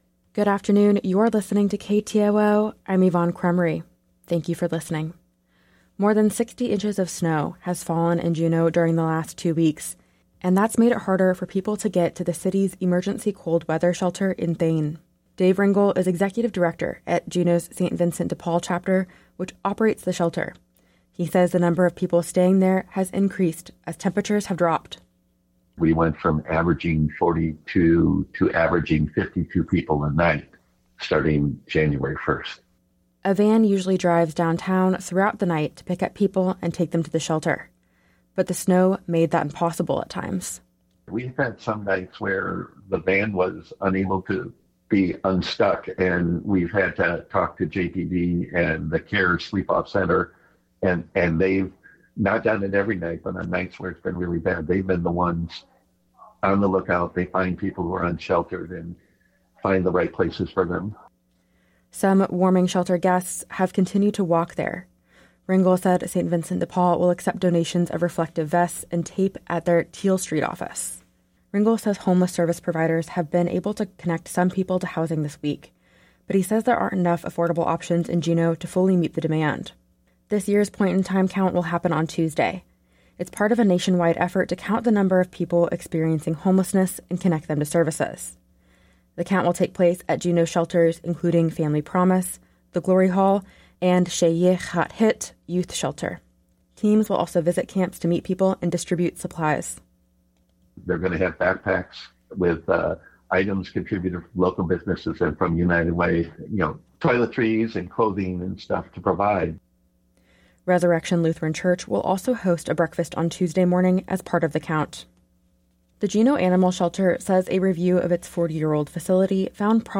Newscast – Thursday, Jan. 25, 2024